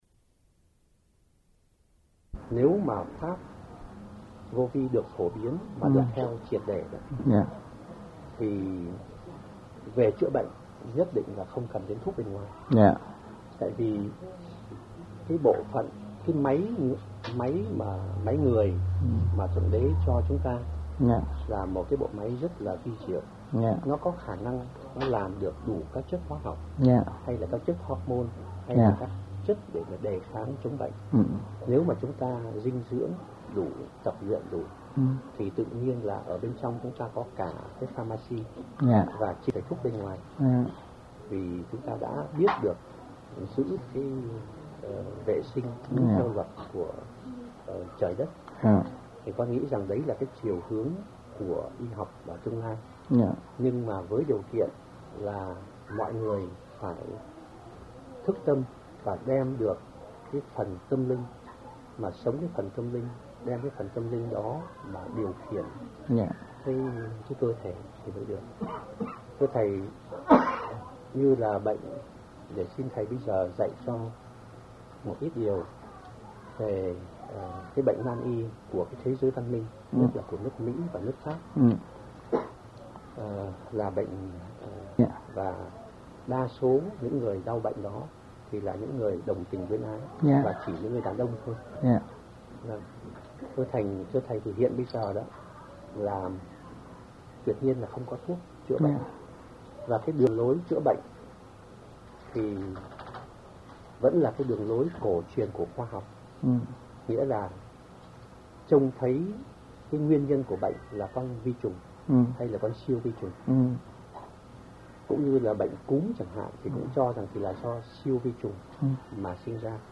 1983 Địa danh : Culver City, California, United States Trong dịp : Sinh hoạt thiền đường >> wide display >> Downloads